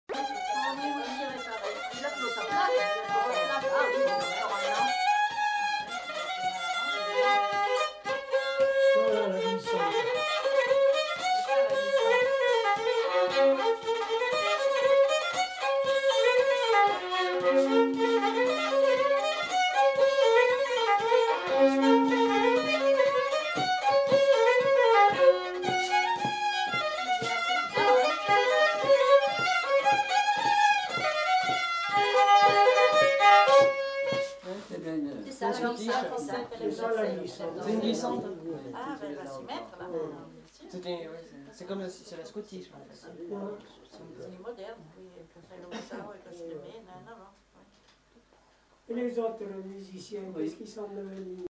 Aire culturelle : Limousin
Lieu : Lacombe (lieu-dit)
Genre : morceau instrumental
Instrument de musique : violon
Danse : scottish
Notes consultables : Le second violon est joué par un des enquêteurs.